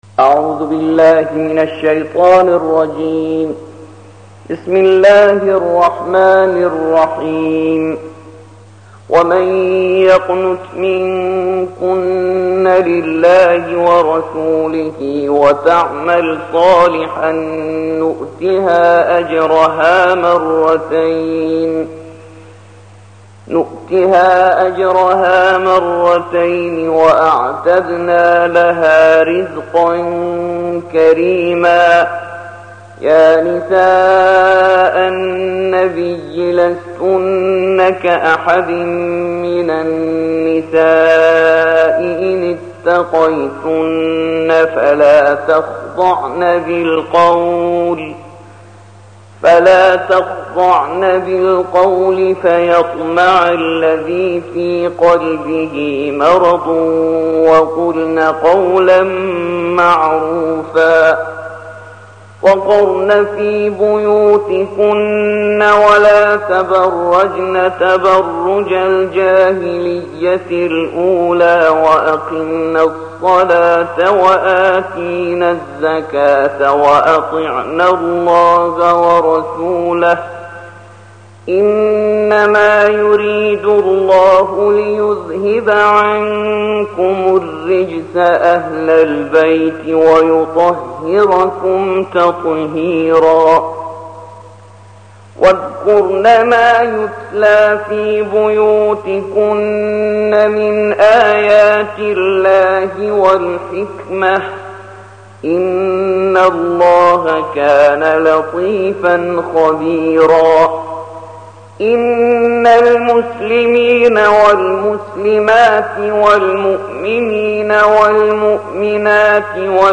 الجزء الثاني والعشرون / القارئ